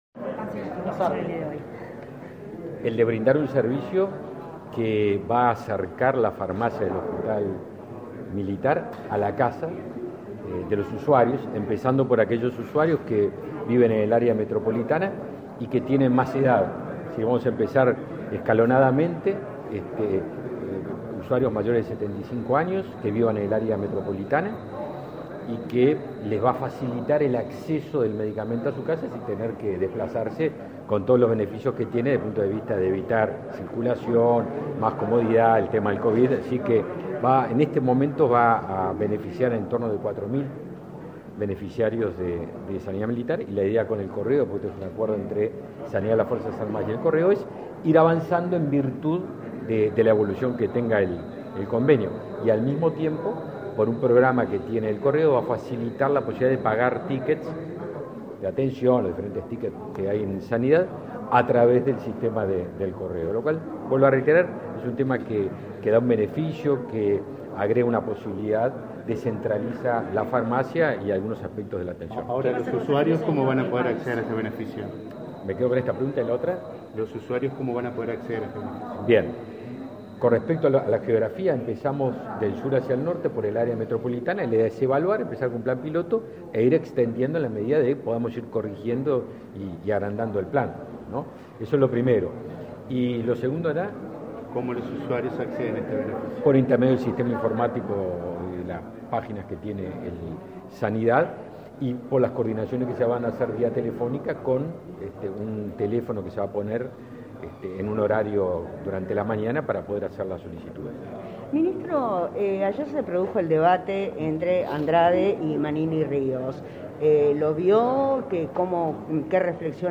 Declaraciones a la prensa del ministro de Defensa Nacional, Javier Gracía